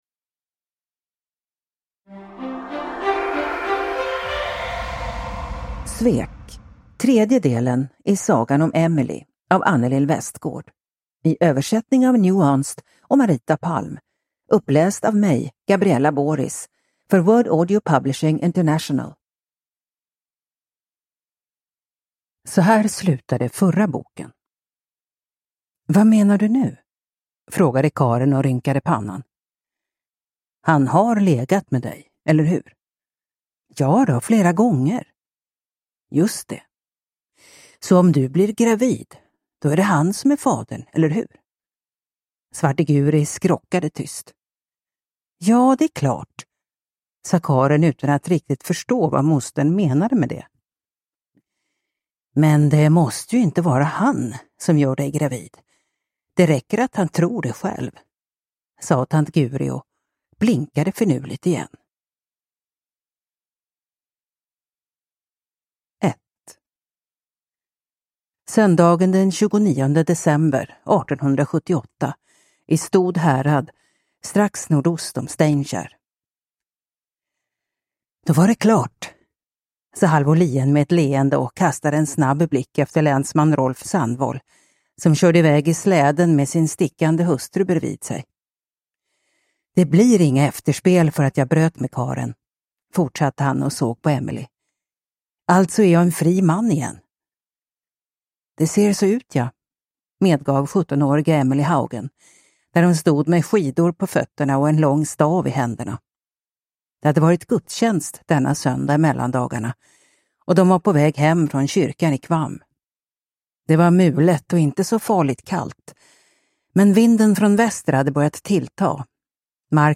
Svek – Ljudbok